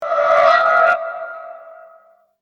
Scary Sound Button - Free Download & Play